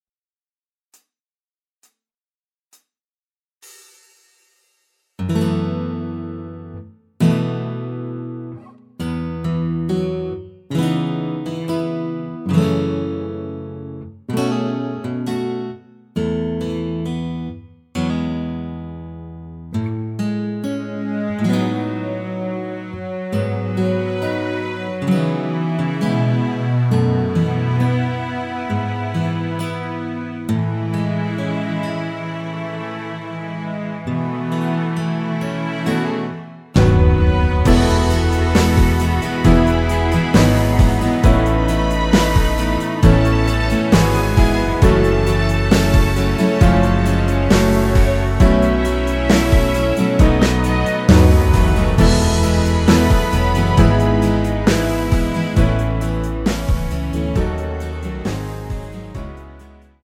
전주 없이 시작 하는곡이라 카운트 넣어 놓았습니다.(미리듣기 참조)
앞부분30초, 뒷부분30초씩 편집해서 올려 드리고 있습니다.
중간에 음이 끈어지고 다시 나오는 이유는